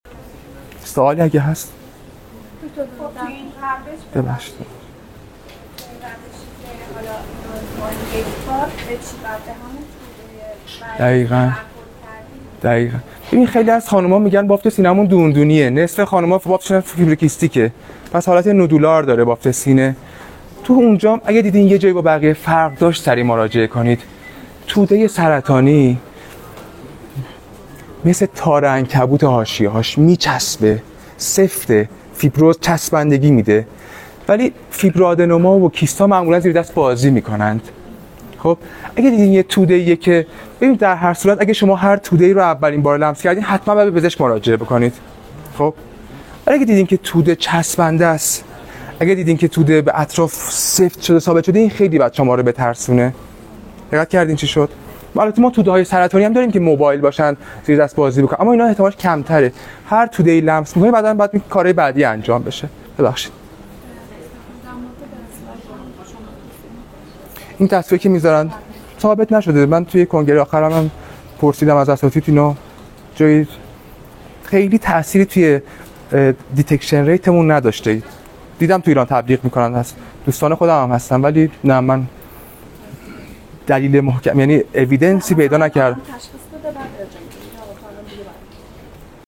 سوال یکی از حاضرین: